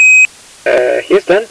fail_astro.ogg